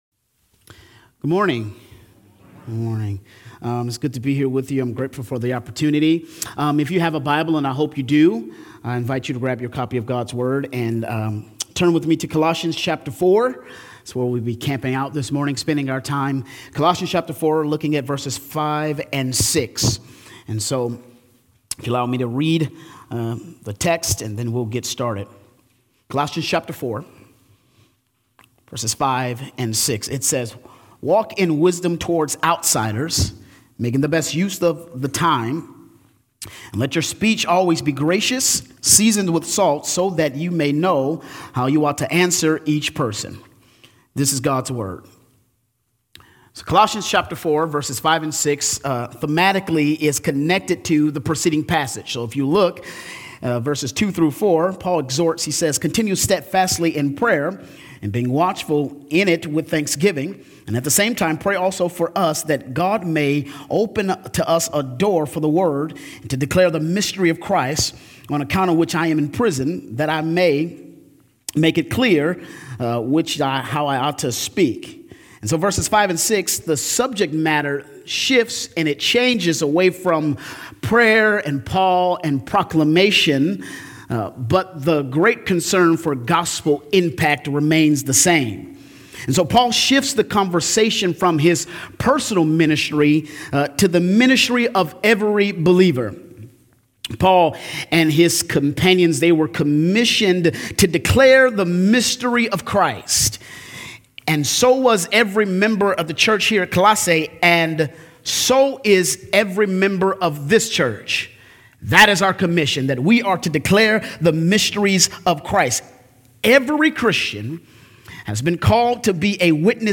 — Sermon text: Colossians 4:5–6